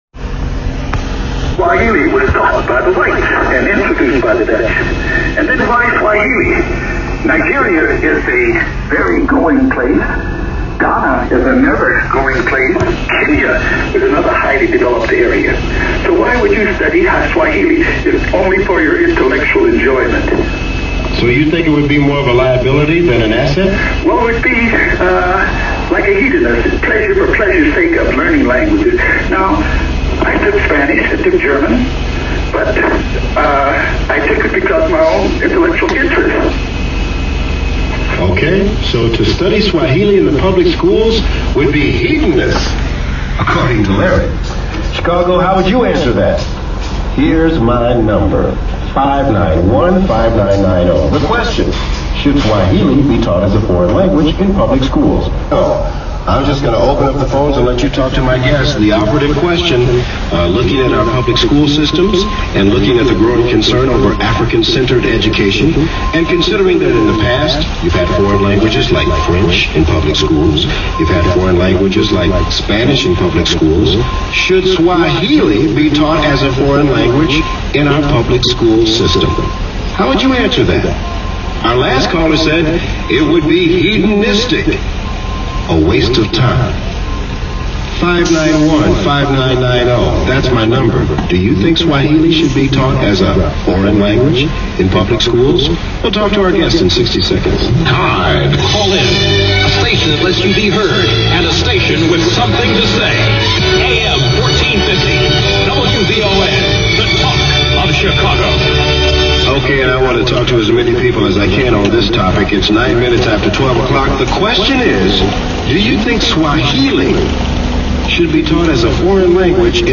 WVON BROADCAST
wvon-discussion.mp3